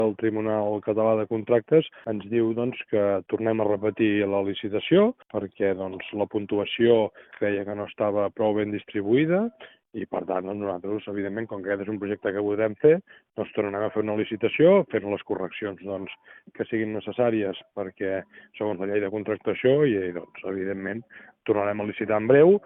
L’alcalde Marc Buch ha indicat a Ràdio Calella TV que es treballa perquè sigui durant l’últim trimestre d’aquest 2025.